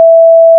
**🔊 SFX PLACEHOLDERS (23 WAV - 1.5MB):**
**⚠  NOTE:** Music/SFX are PLACEHOLDERS (simple tones)
scythe_swing.wav